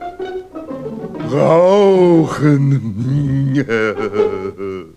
Der König sagt in dieser Fassung "Killer" und der Riese (der später gleich ganz stumm blieb) ganz laut "Rauchen".